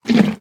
Minecraft Version Minecraft Version snapshot Latest Release | Latest Snapshot snapshot / assets / minecraft / sounds / mob / horse / eat4.ogg Compare With Compare With Latest Release | Latest Snapshot
eat4.ogg